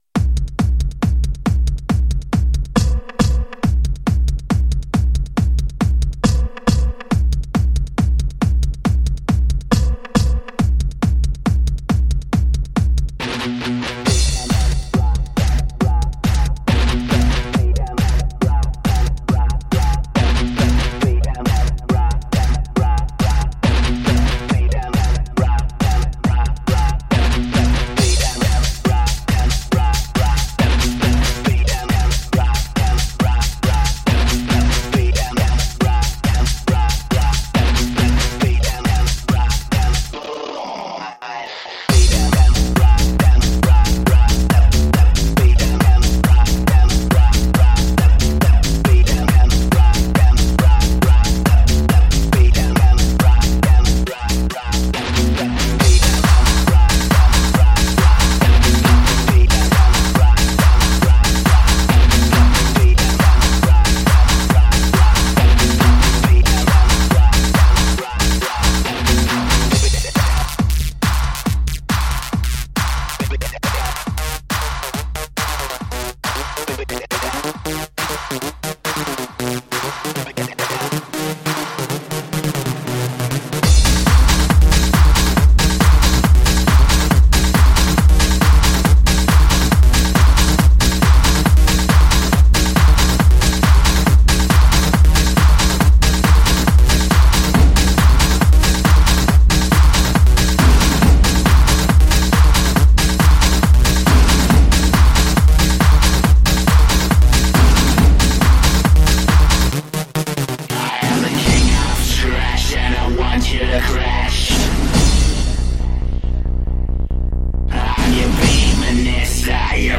Жанр: Dance/Electronic